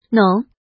怎么读
nǒng
nong3.mp3